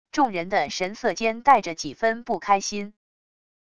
众人的神色间带着几分不开心wav音频生成系统WAV Audio Player